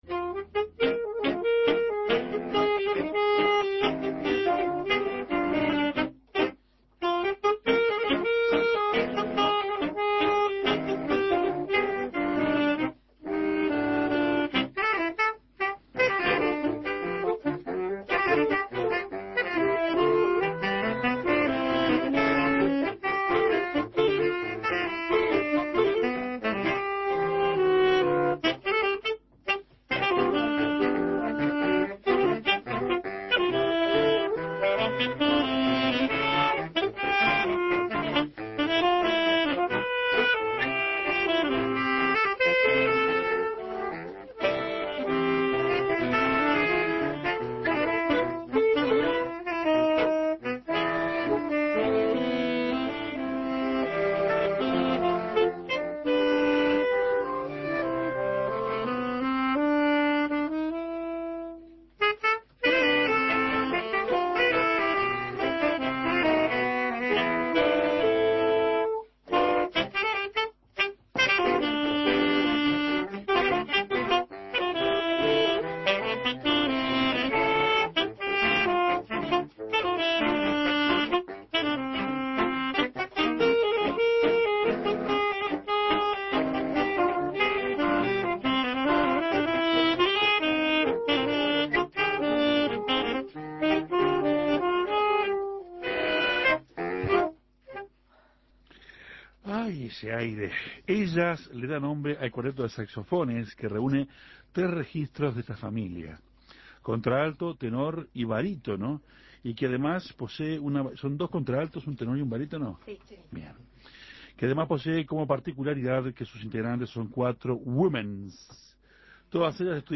El cuarteto de saxofones Ellas visitó Café Torrado y habló de su historia. Reunidas en la Escuela Municipal de Música, estas cuatro mujeres decidieron llevar adelante su pasión: tocar el saxofón.